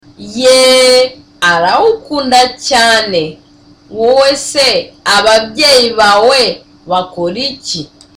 Dialogue: A conversation between Tereza and Sonita
(Relaxed too)